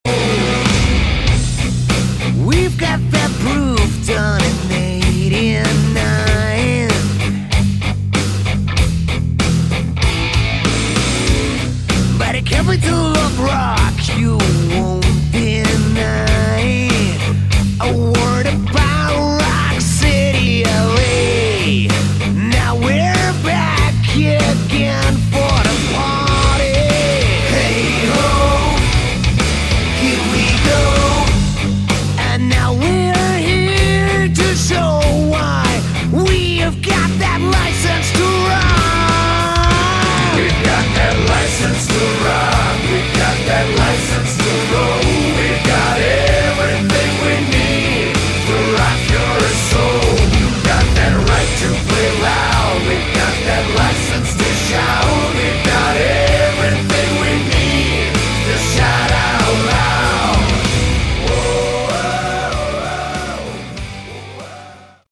Category: Hard Rock
vocals
guitar
drums
bass